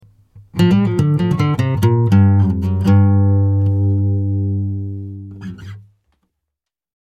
Blues riff on acoustic guitar
Blues+riff+on+acoustic+guitar_AOS01116.mp3